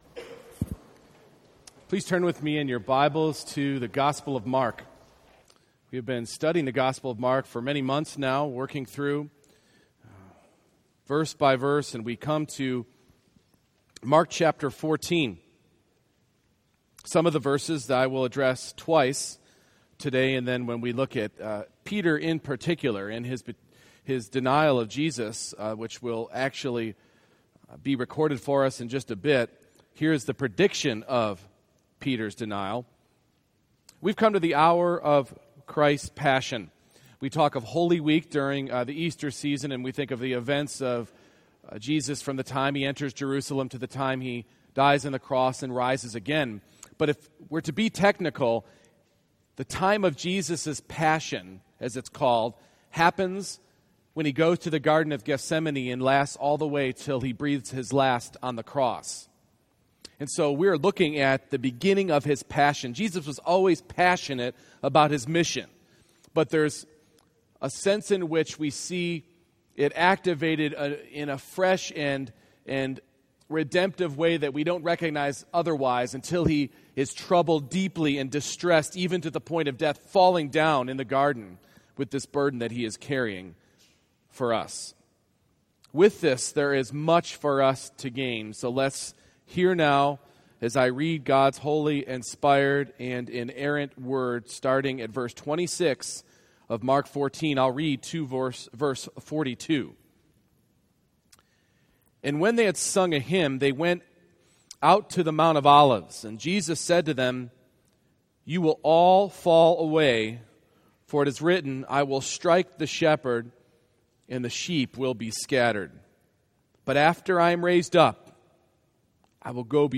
Mark 14:26-42 Service Type: Morning Worship Christ is not just an aid to our weakness